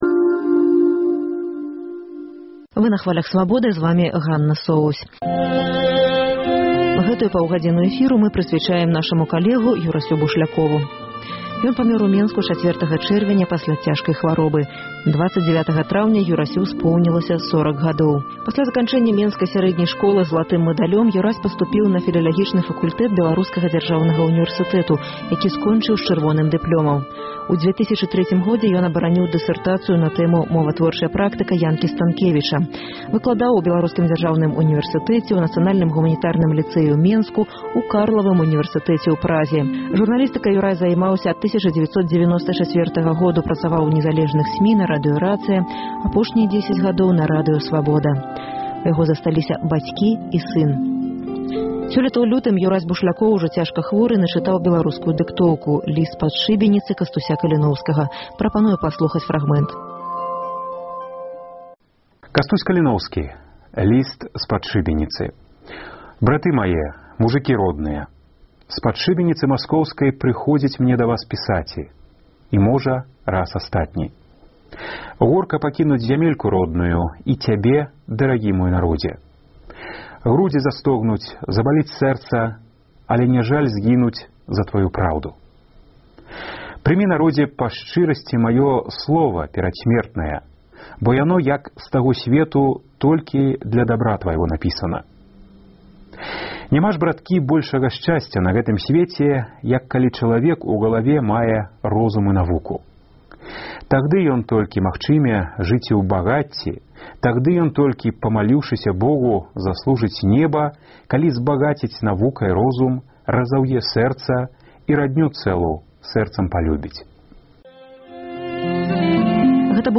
Мы падрыхтавалі спэцыяльную паўгадзінную перадачу